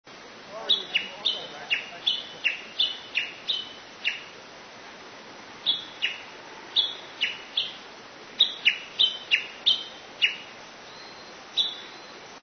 上空にはトンビが舞い、ホール周りの木々では春ゼミが鳴き、
ウグイス等の野鳥が囀る。